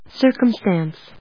音節cir・cum・stance 発音記号・読み方
/sˈɚːkəmst`æns(米国英語), ˈsɜ:kʌˌmstæns(英国英語)/